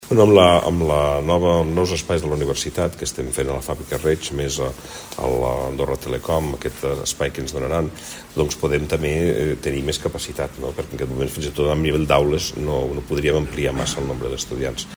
Minoves ha fet aquestes declaracions durant la roda de premsa posterior a la inauguració del Seminari CRUE-UdA, celebrat a Sant Julià de Lòria.